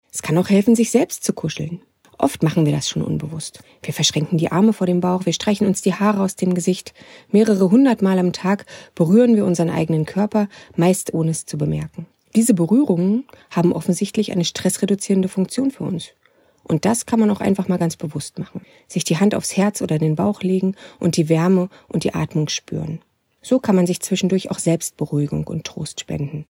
Radio O-Töne